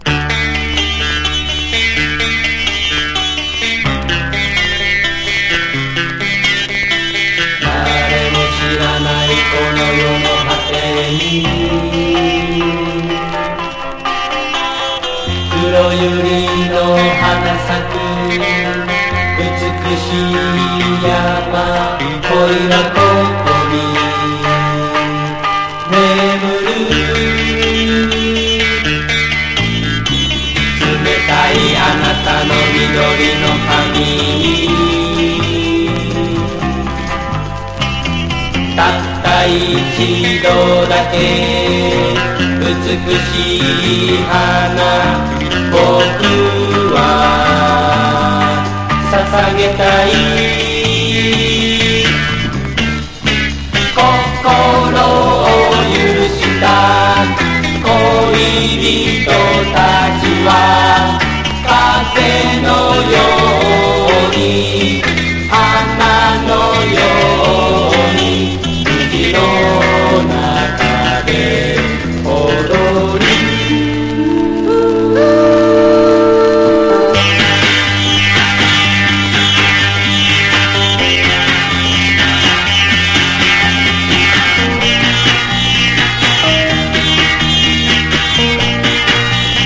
シタールを取り入れたポップでサイケなナンバー
60-80’S ROCK